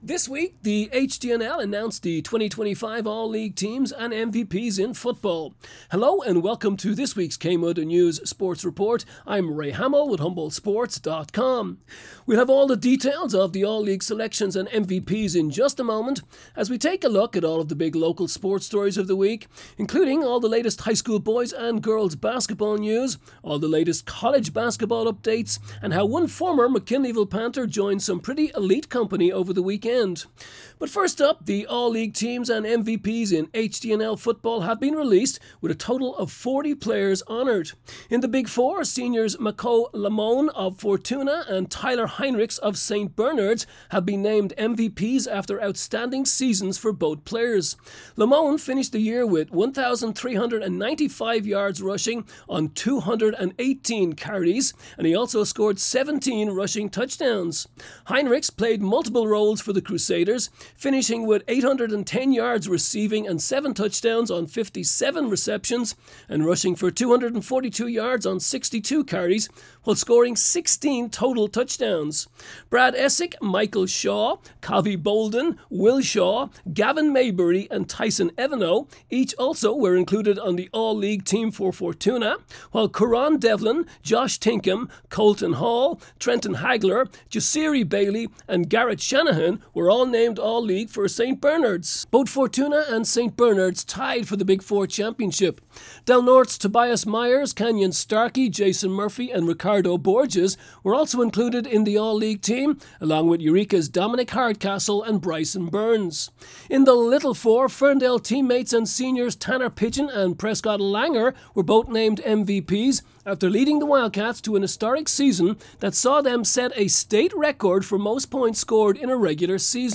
KMUD News Sports Report